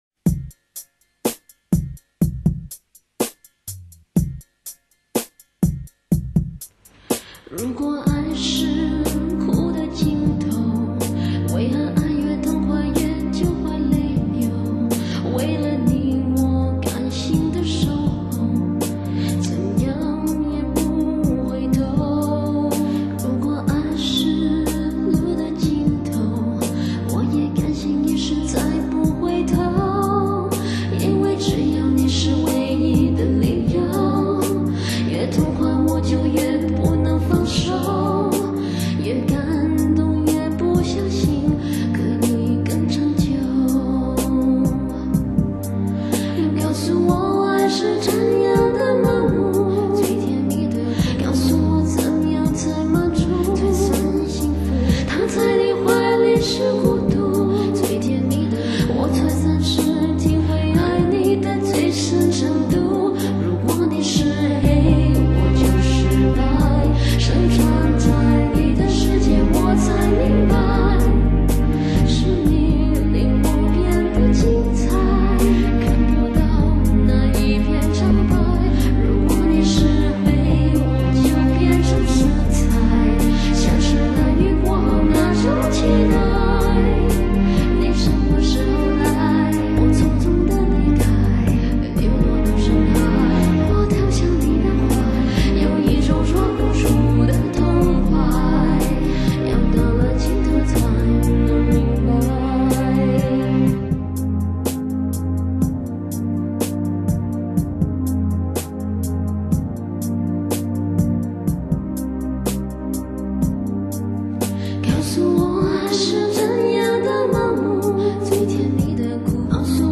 不知名的二重唱演绎
单曲进行到0:08开始的女声